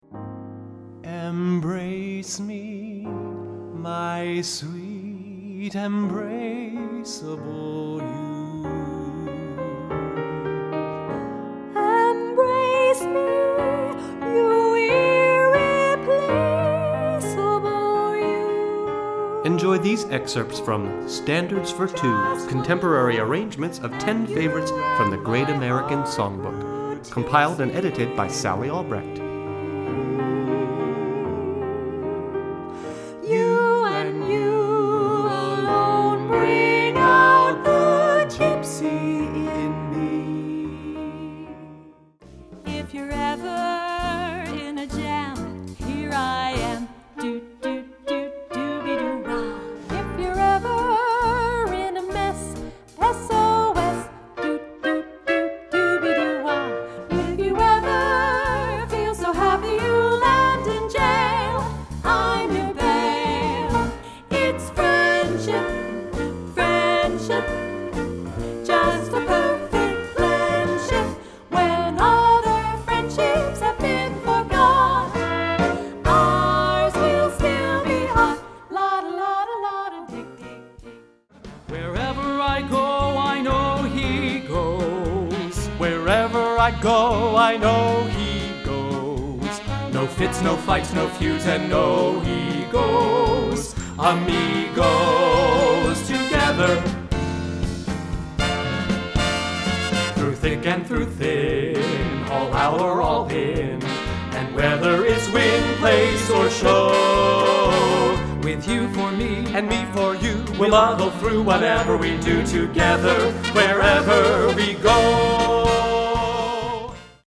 Voicing: Vocal Duet